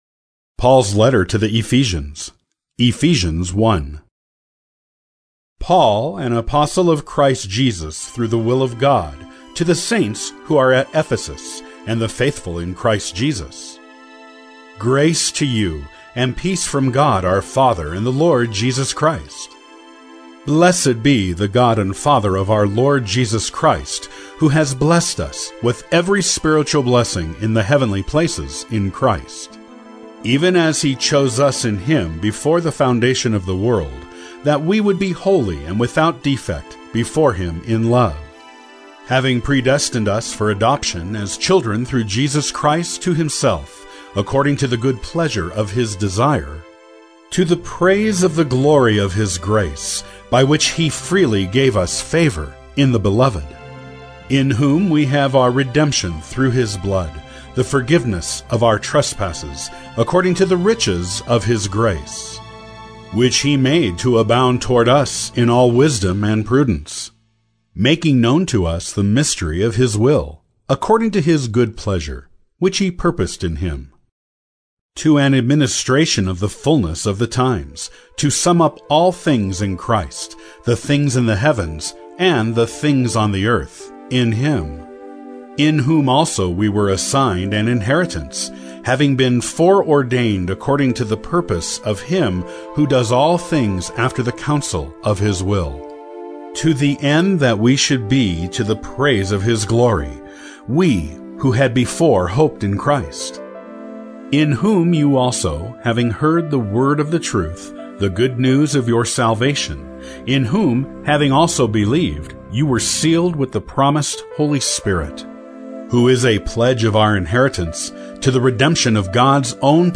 World English MP3 Bible, Drama